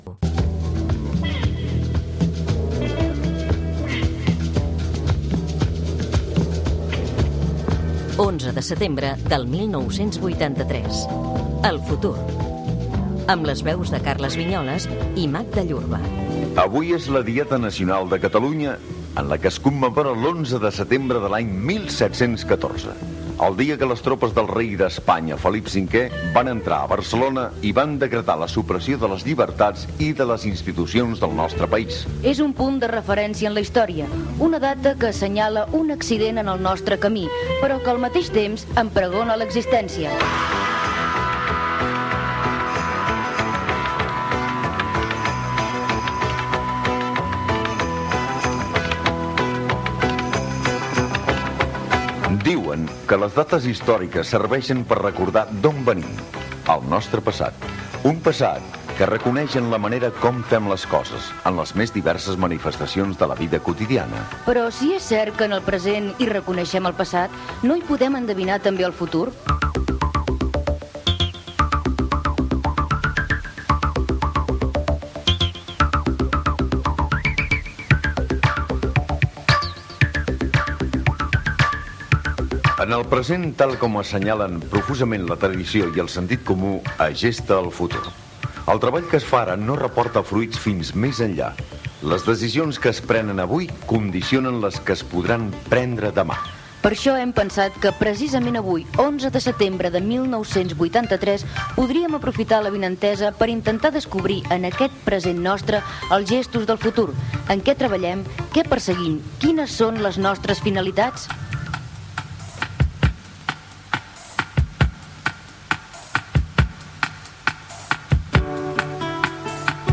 Què és commemora en la Diada de Catalunya i el seu futur. Diverses personalitats diuen quins són els seus objectius personals
Gènere radiofònic Divulgació